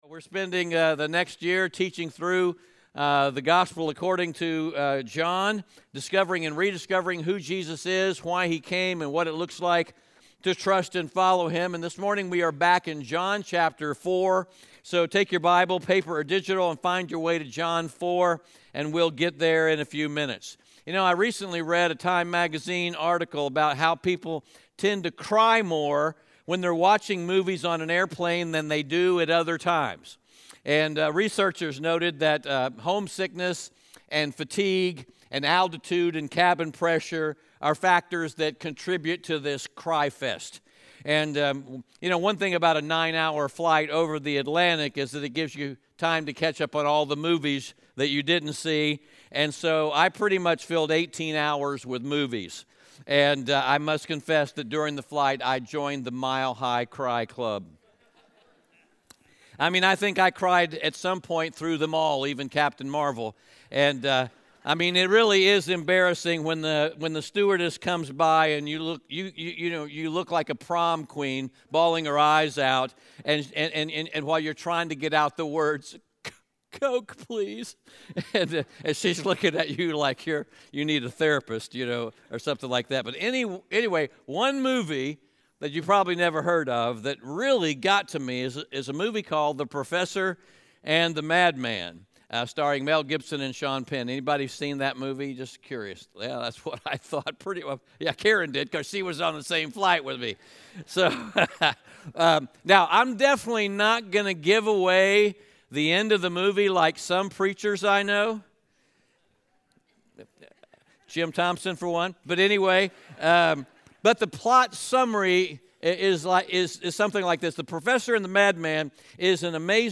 John 4:27-42 Audio Sermon Notes (PDF) Ask a Question Scripture: John 4:27-42 All of us have probably known someone who you would describe as a “man or woman on mission.”